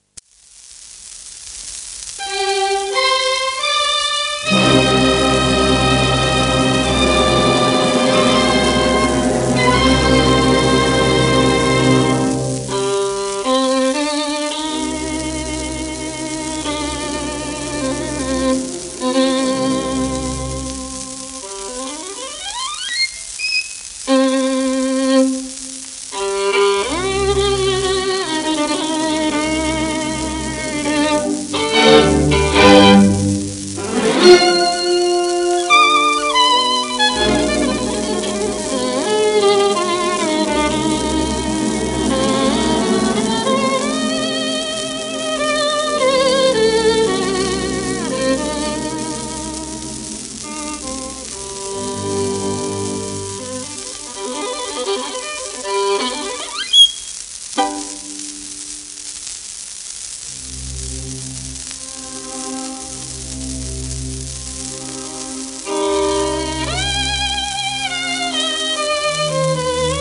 1937年録音
シェルマン アートワークスのSPレコード